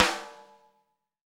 Index of /90_sSampleCDs/Roland L-CDX-01/SNR_Rim & Stick/SNR_Rim Modules